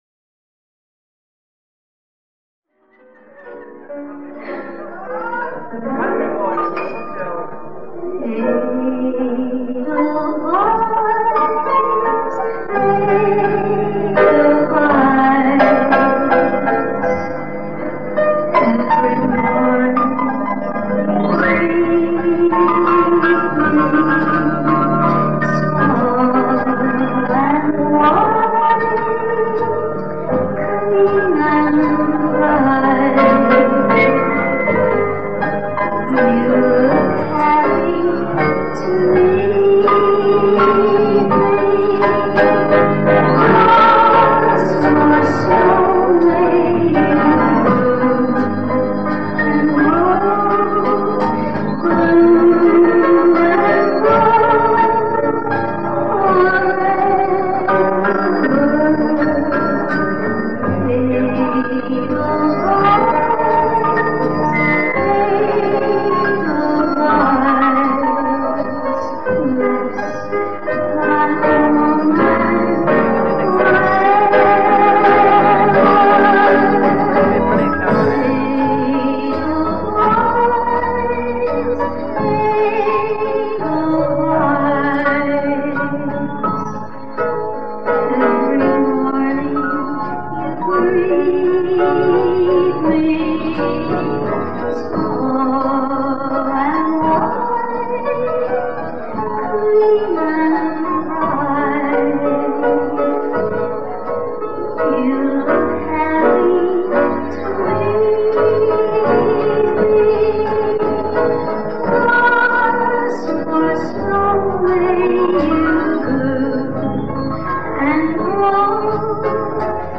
recorded during a Club act